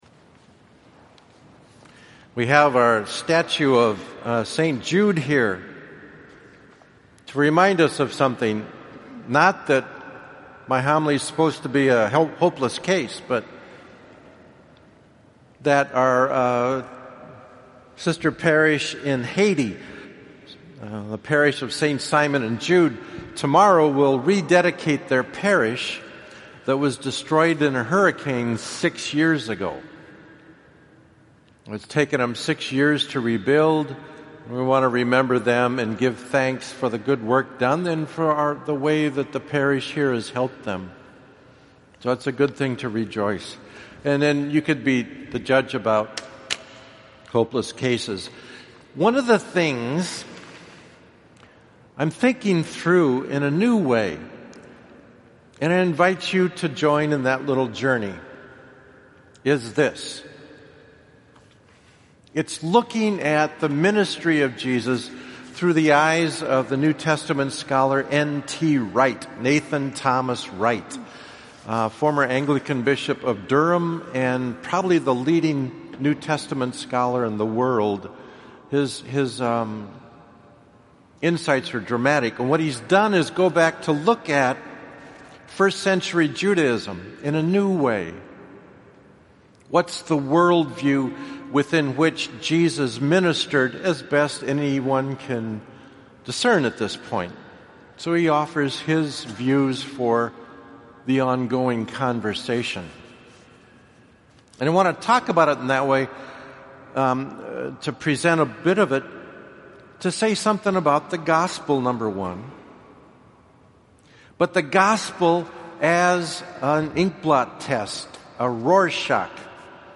Homily – Fifteenth Sunday Ordinary Time 2016